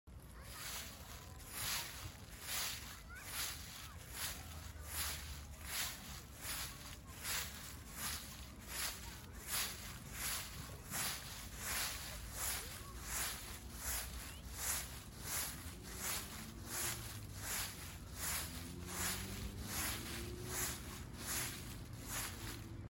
Leaves sound asmr walking sound effects free download
Leaves sound asmr - walking through leaves in the field